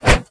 naga_warrior_swish.wav